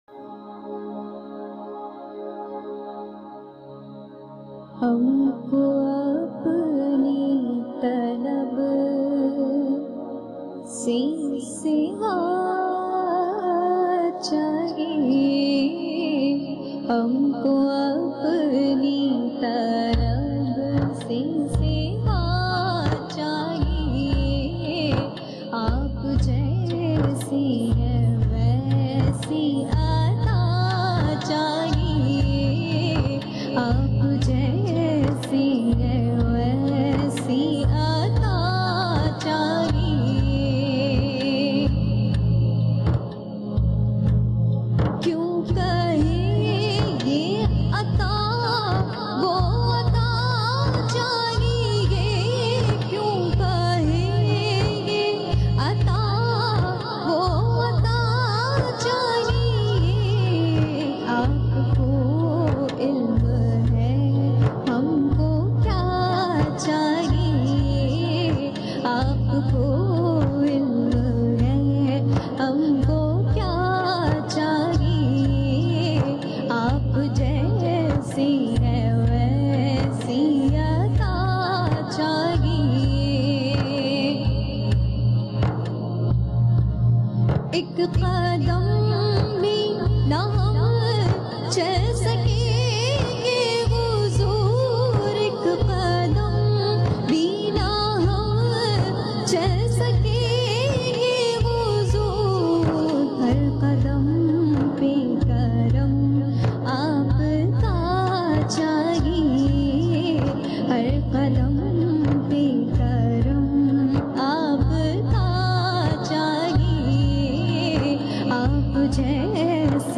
Naat Lyrics